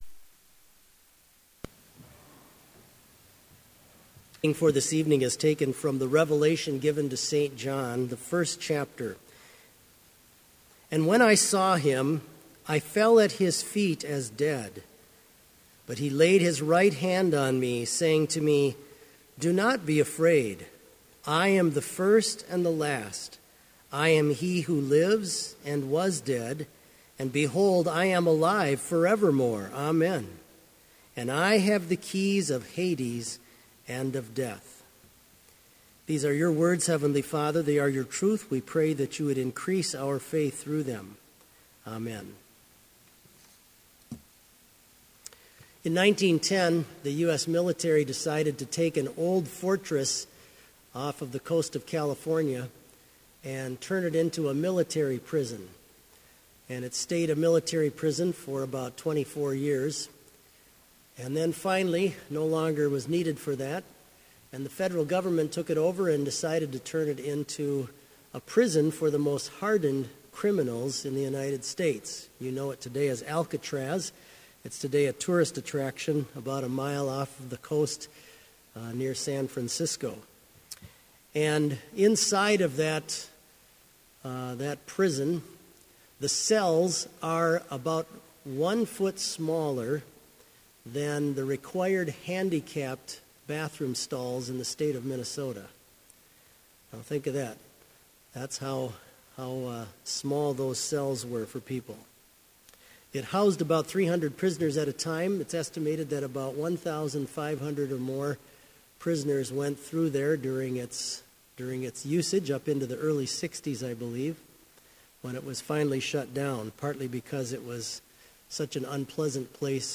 Sermon audio for Evening Vespers - March 30, 2016